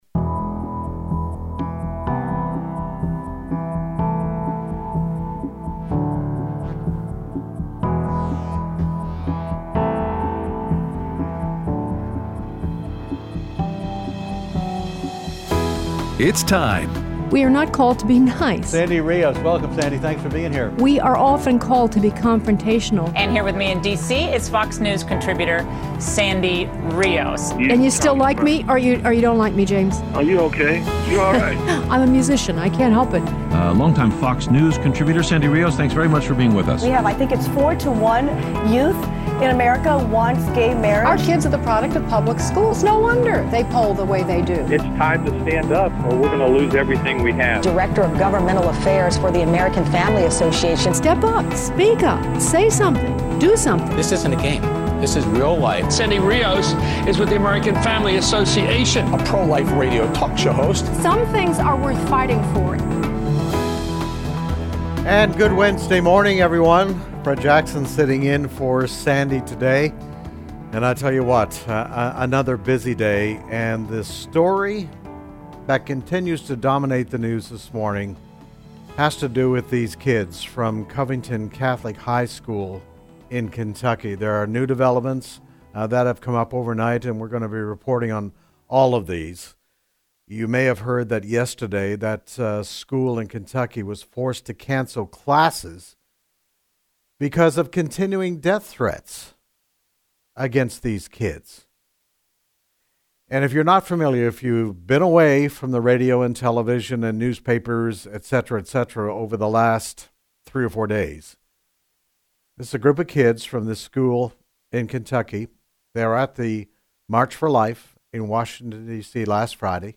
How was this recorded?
Aired Wednesday 1/23/19 on AFR 7:05AM - 8:00AM CST